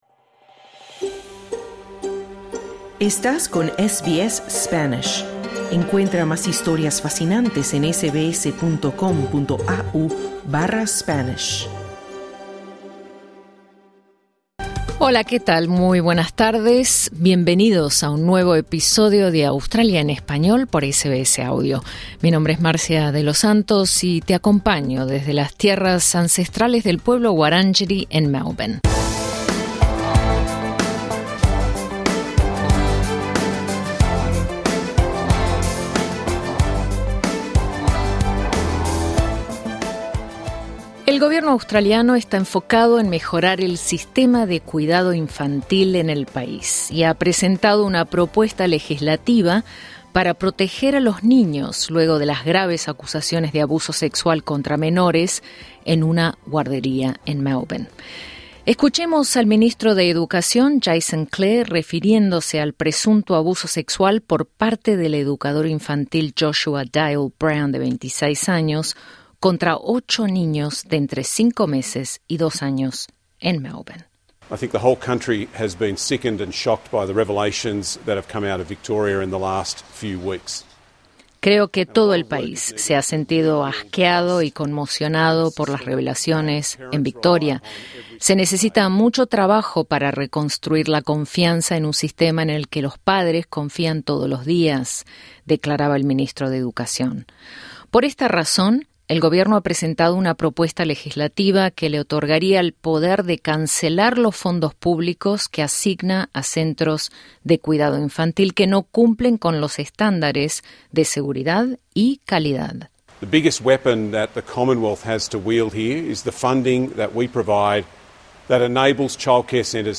El gobierno australiano y expertos están enfocados en entender por qué algunos centros de cuidado infantil no cumplen con las normas regulatorias para los servicios de guardería y educación preescolar. Conversamos con una exeducadora del sector que dice que renunció por razones de salud mental.